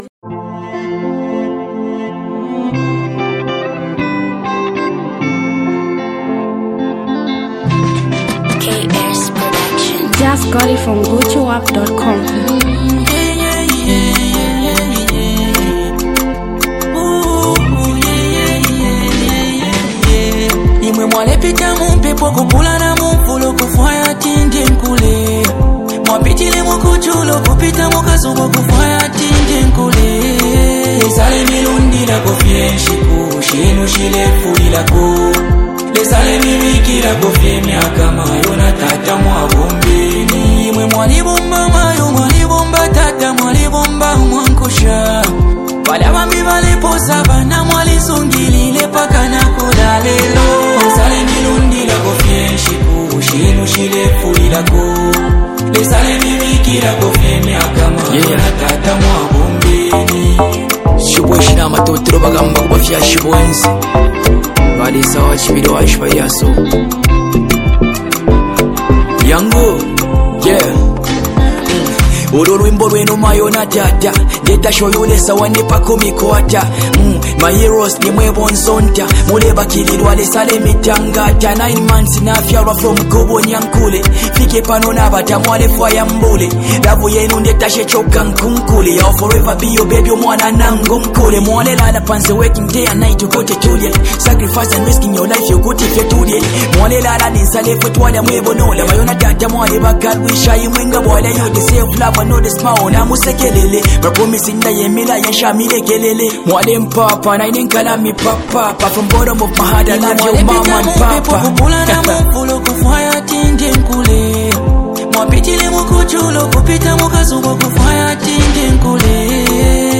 singers, Songwriters and Rappers